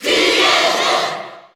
Category: Crowd cheers (SSBU) You cannot overwrite this file.
Cloud_Cheer_Japanese_SSBU.ogg.mp3